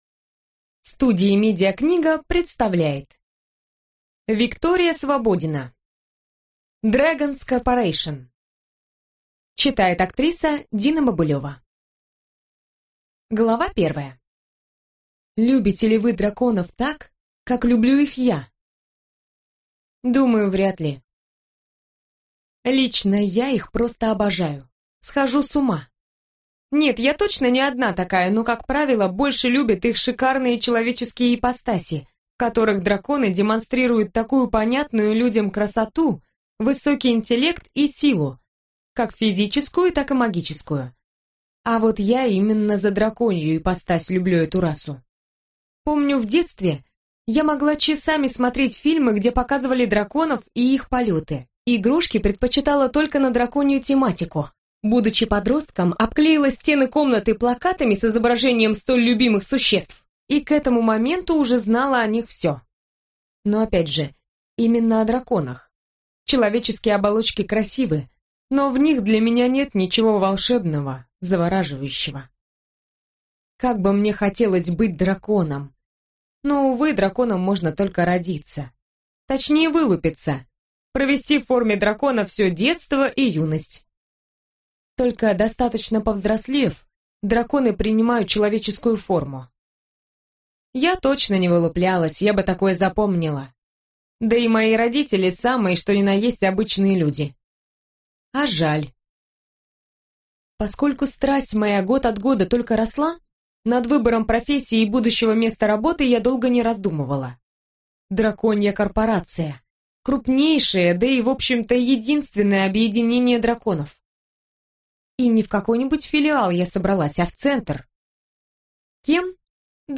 Аудиокнига Dragons corporation | Библиотека аудиокниг
Прослушать и бесплатно скачать фрагмент аудиокниги